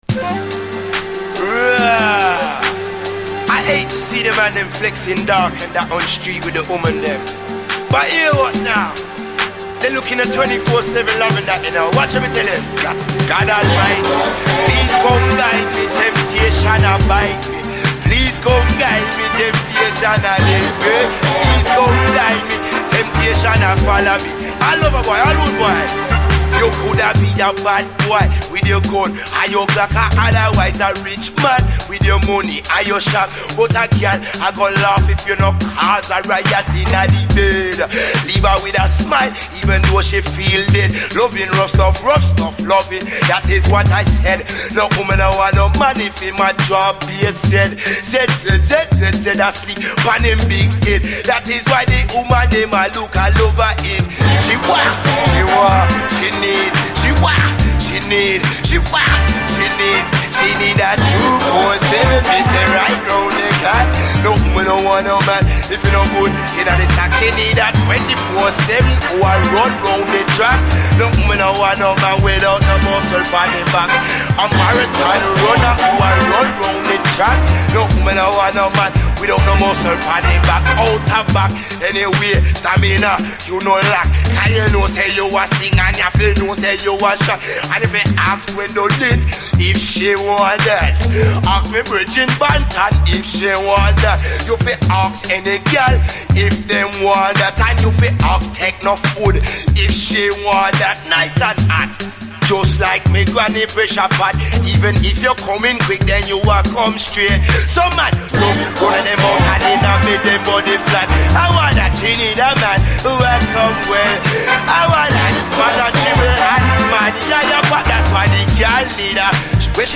SOUL, 70's～ SOUL, REGGAE